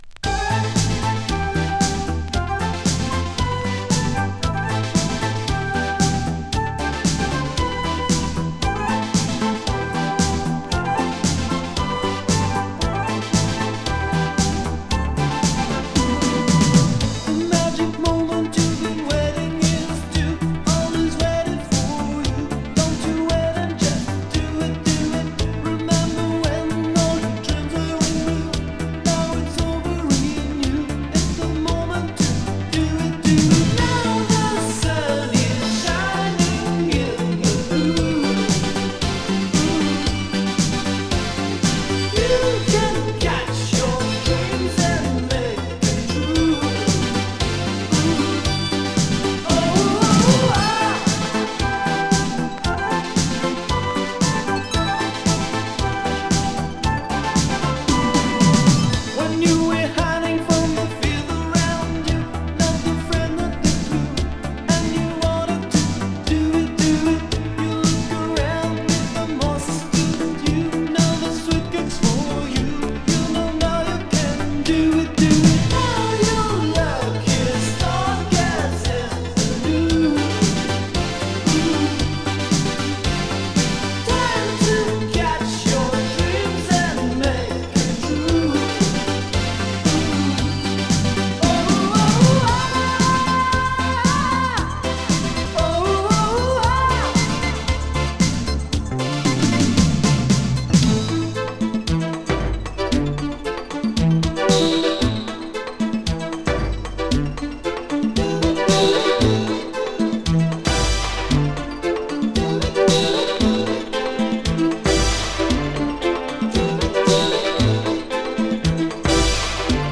マイナー哀愁系イタロ・ディスコ！
マイナー男性ディスコ・シンガー。
【ITALO DISCO】